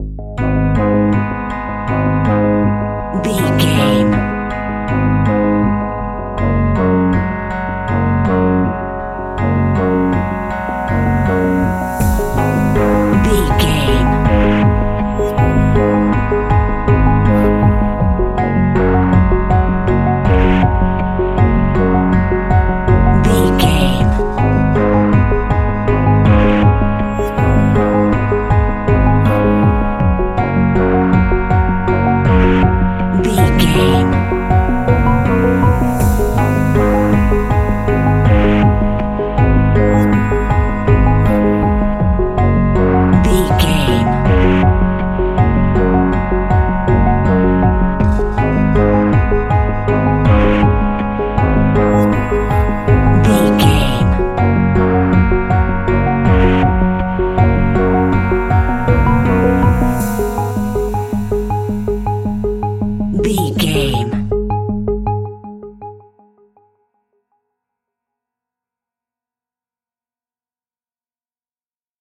Aeolian/Minor
G#
Slow
ominous
dark
eerie
piano
synthesiser
drums
horror music